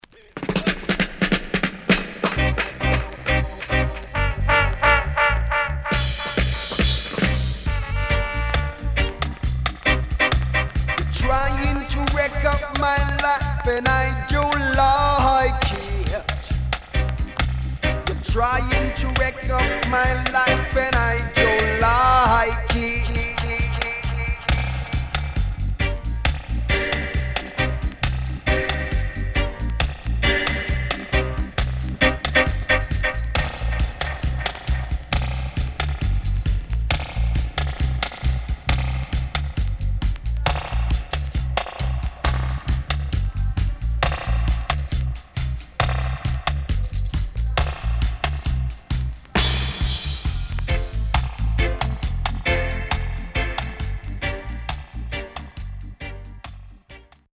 riddim
SIDE A:所々チリノイズがあり、少しプチノイズ入ります。